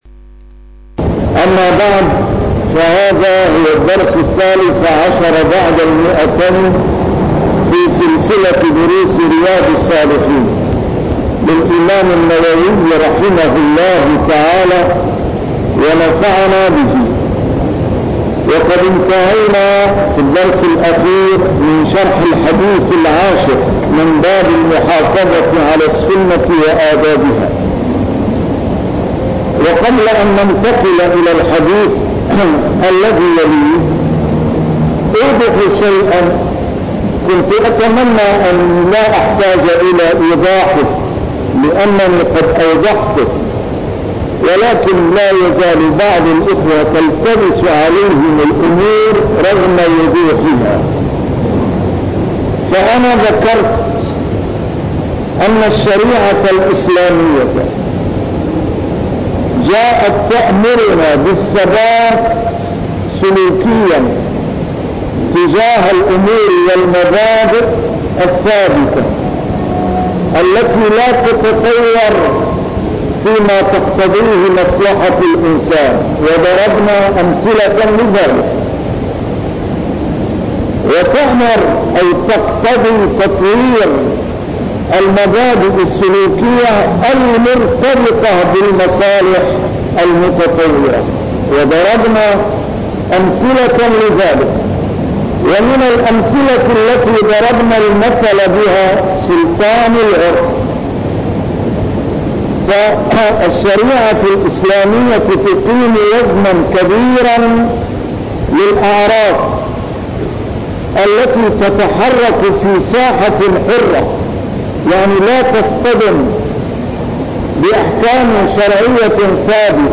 A MARTYR SCHOLAR: IMAM MUHAMMAD SAEED RAMADAN AL-BOUTI - الدروس العلمية - شرح كتاب رياض الصالحين - 213- شرح رياض الصالحين: المحافظة على السنة